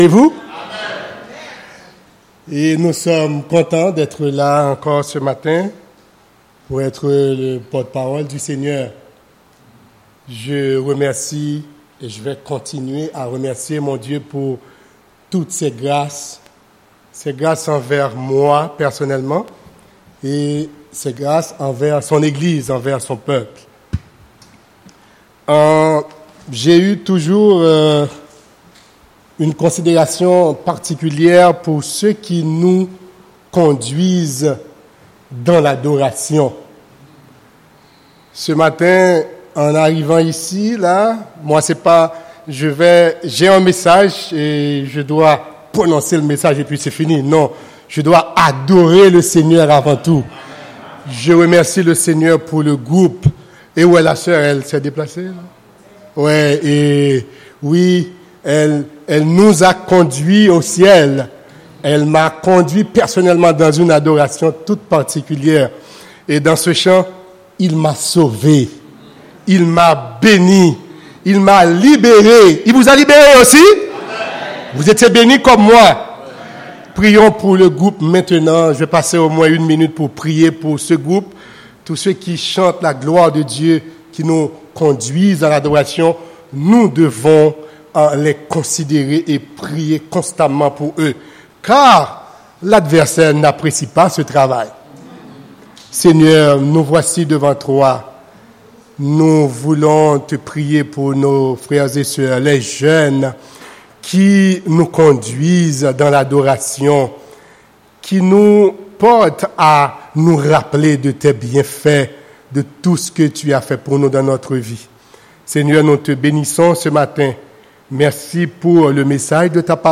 Passage: Colossiens 2 : 1-7 Type De Service: Dimanche matin